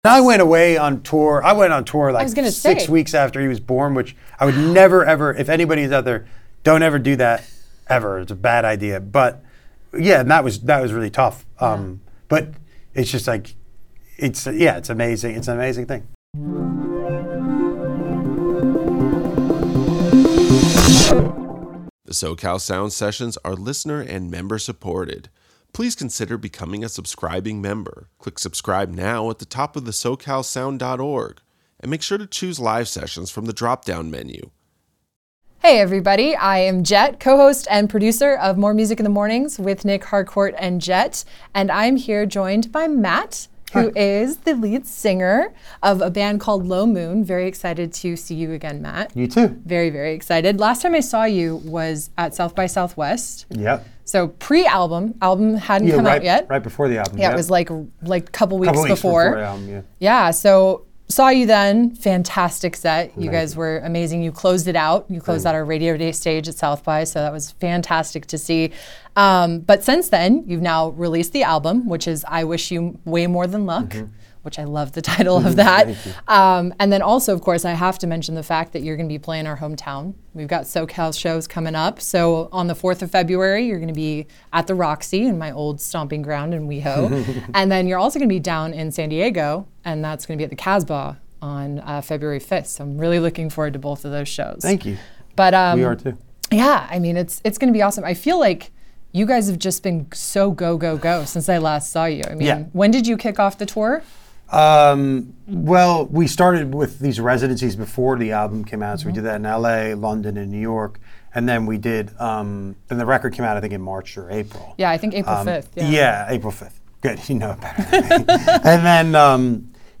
Los Angeles's public alternative rock radio.